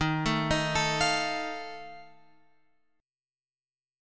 D# Suspended 2nd Flat 5th